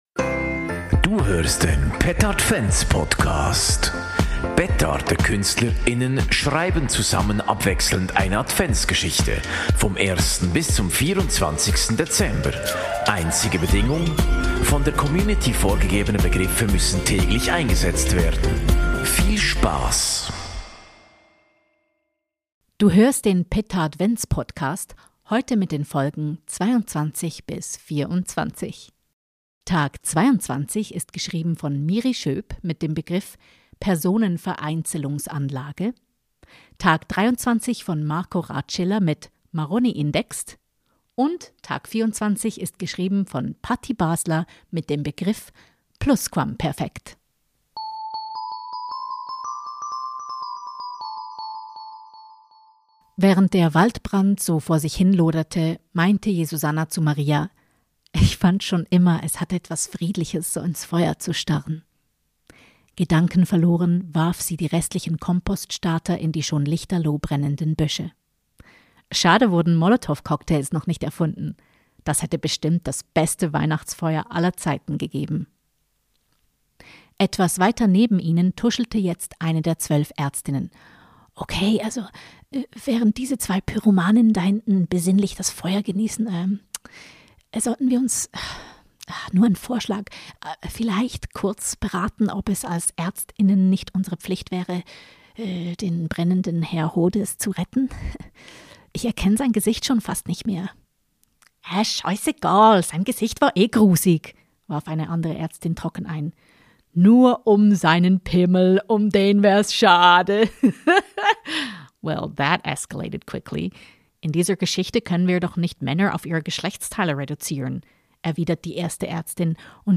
Der Petardvent Podcast ist ein Adventspodcast der Satiremarke Petarde. Petardekünstler:innen schreiben abwechselnd eine Adventsgeschichte vom ersten bis zum 24. Dezember.